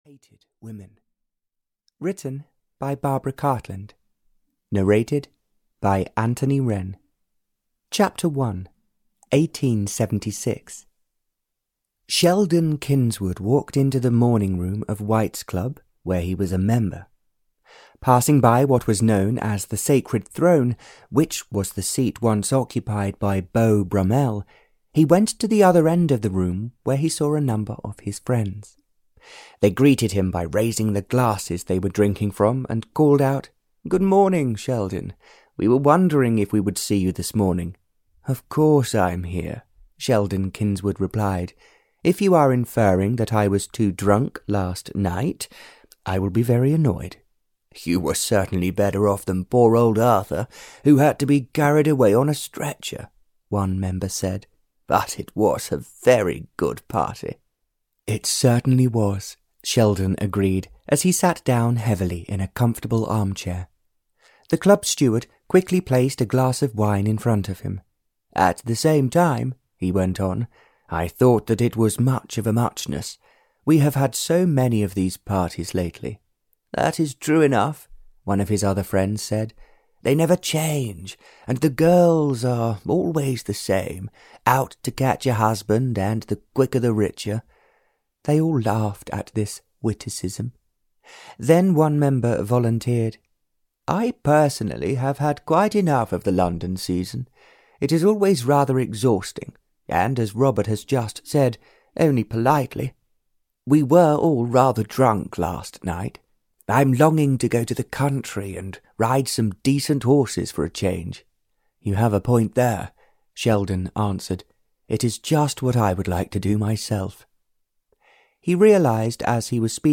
Audio knihaThe Duke Hated Women (Barbara Cartland's Pink Collection 145) (EN)
Ukázka z knihy